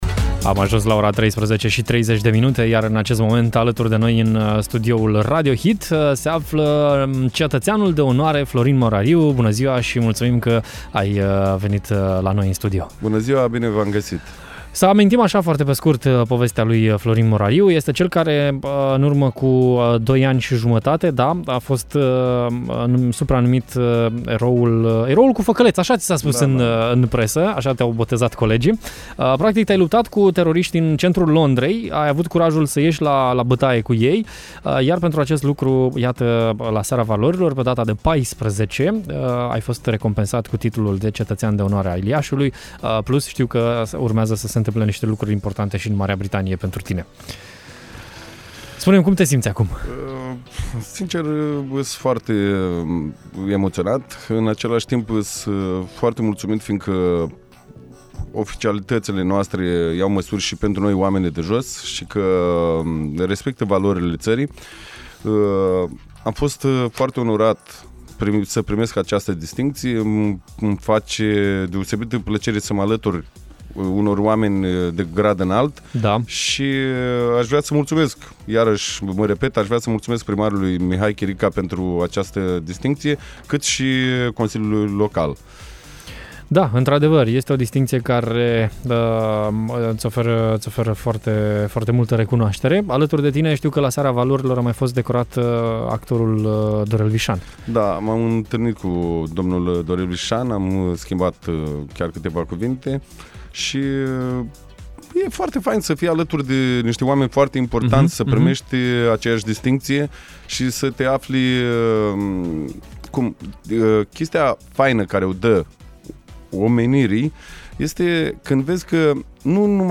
Acum lucrează la o carte despre viața lui. Am aflat detalii în direct la Radio Hit: